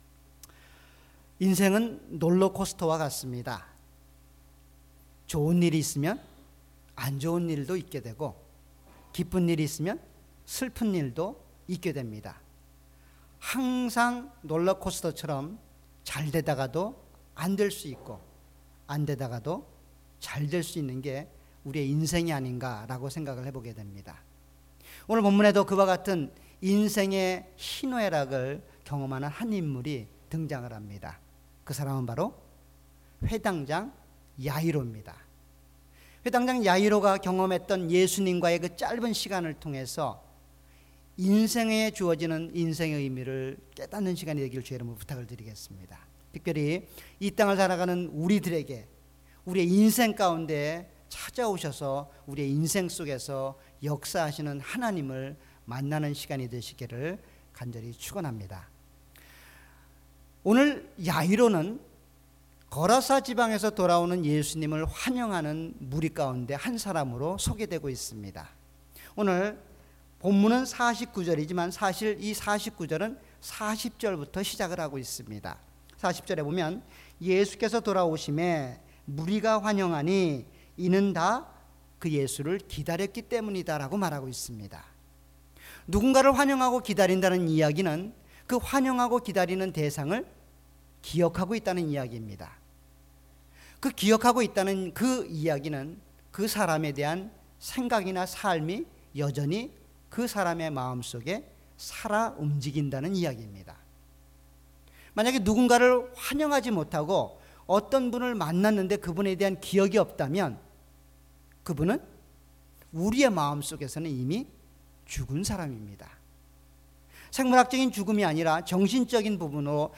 주일예배.Sunday